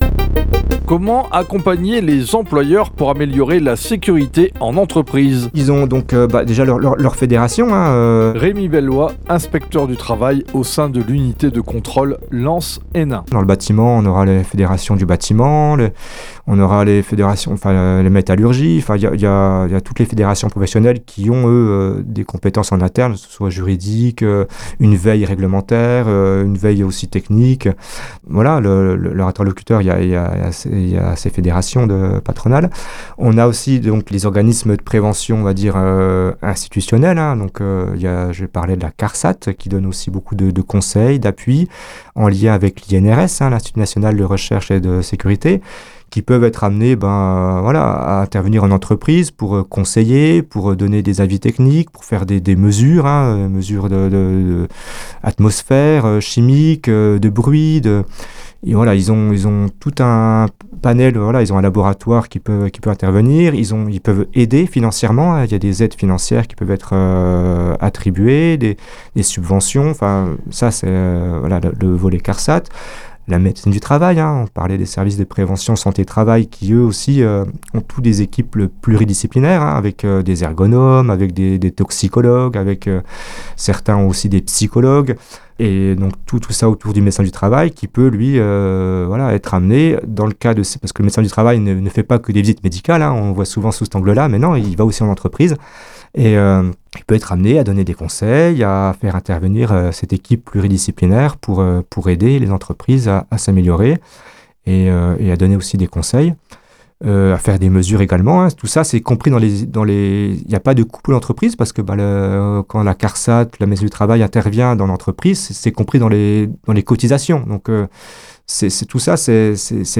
8 spots radio diffusés à l’automne 2025 :